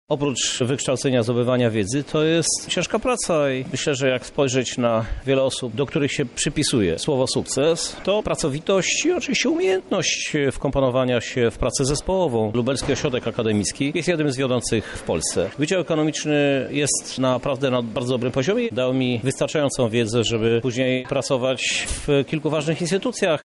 Dzisiaj na nurtujące pytania przyszłych studentów odpowiedział prezydent Lublina, Krzysztof Żuk.
Najważniejsza jest ciężka praca- mówi prezydent miasta.